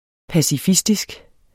Udtale [ pasiˈfisdisg ]